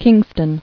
[King·ston]